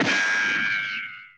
Звук удара пули о стену